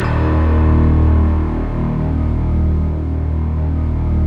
Index of /90_sSampleCDs/Optical Media International - Sonic Images Library/SI1_Rich&Mellow/SI1_Mellow Pad
SI1 PLUCK00L.wav